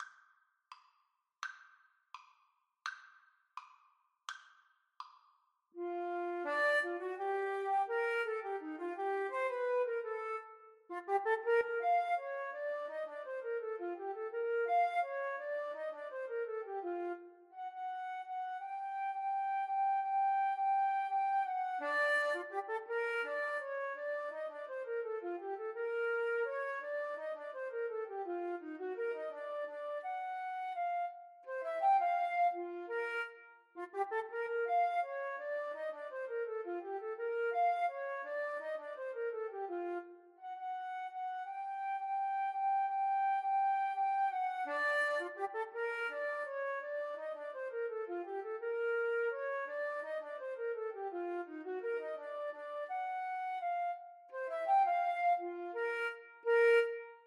Free Sheet music for Flute Duet
Flute 1Flute 2
= 84 Slow March Time
2/4 (View more 2/4 Music)
Bb major (Sounding Pitch) (View more Bb major Music for Flute Duet )
Jazz (View more Jazz Flute Duet Music)